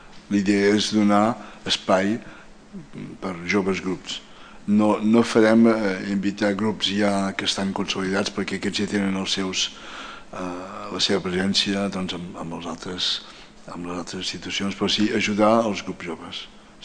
ÀUDIO: Jordi Savall destaca la inclusió de formacions emergents